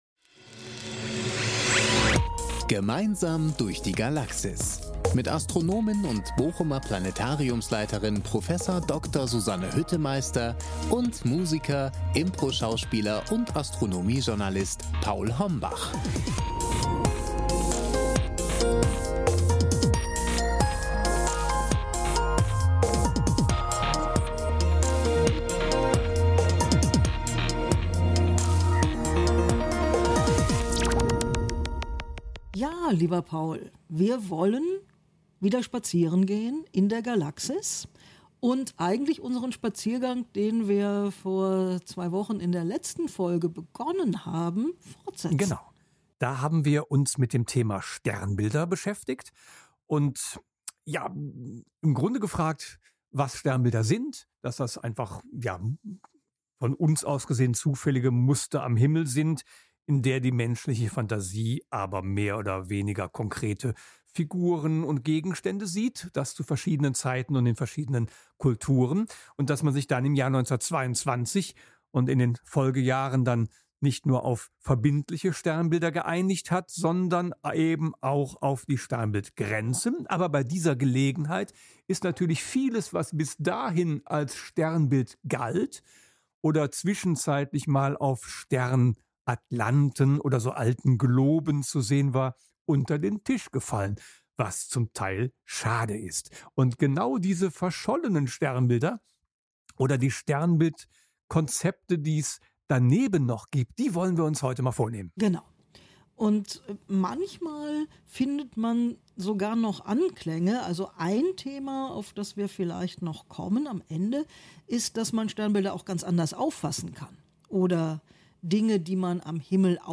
Astronomische Plaudereien aus dem Planetarium Bochum